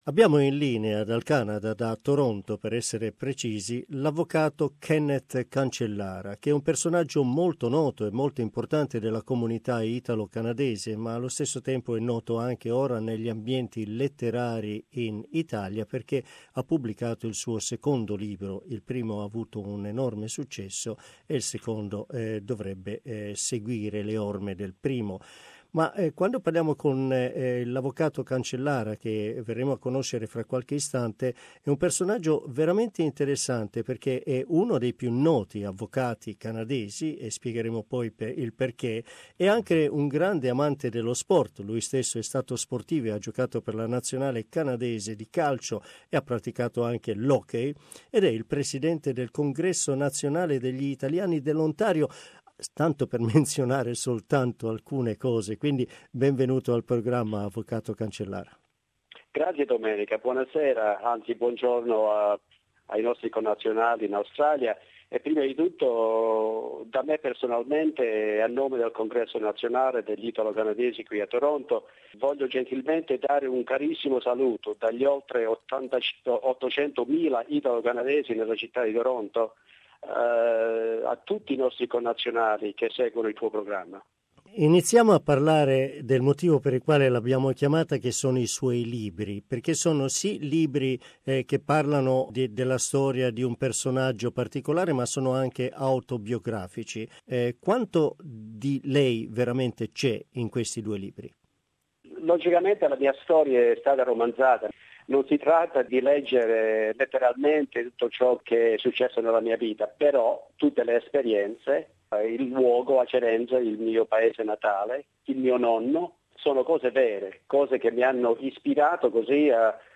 He tells much more in this interview.